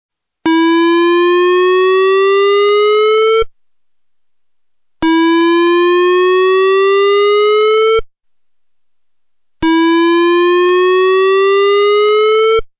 Occoquan Dam Siren
The siren is a loud whooping noise.
whoop.mp3